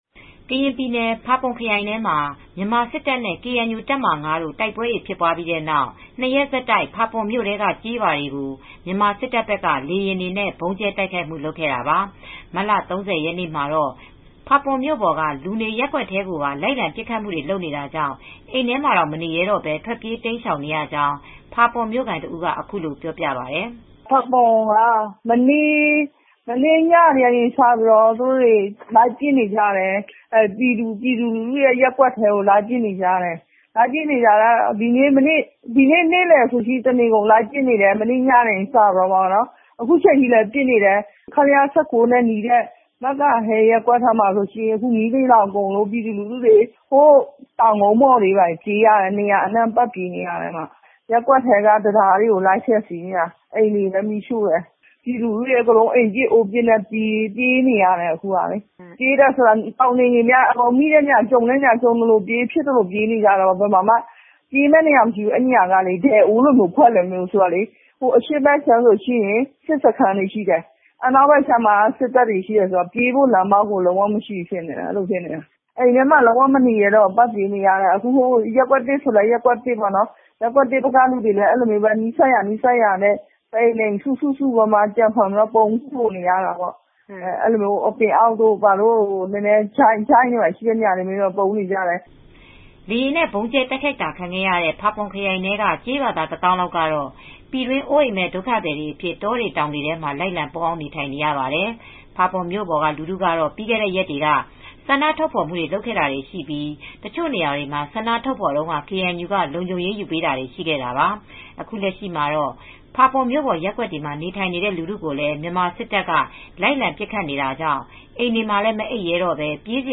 ကရင်ပြည်နယ် ၊ ဖာပွန်မှာ မြန်မာစစ်တပ်နဲ့ KNU တပ်မဟာ(၅)တို့ တိုက်ပွဲတွေဖြစ်ပြီးတဲ့နောက် ၂ရက်ဆက်တိုက် ဖာပွန်မြို့နယ်ထဲက ကျေးရွာကို မြန်မာစစ်တပ်ဘက်က လေယာဉ်တွေနဲ့ ဗုံးကြဲ တိုက်ခိုက်မူတွေ လုပ်ခဲ့တာပါ။ မတ်လ ၃၀ ရက်နေ့မှာတော့ ဖာပွန်မြို့ပေါ်ကလူနေရပ်ကွက်တွေထဲကို လိုက်လံပစ်ခတ်မူ လုပ်နေတာကြောင့် အိမ်ထဲတွေမှာတောင် မနေရဲတော့ပဲ ထွက်ပြေးတိမ်းရှောင်နေရကြောင်း ဖာပွန်မြို့ခံတဦးက အခုလို ပြောပြပါတယ်။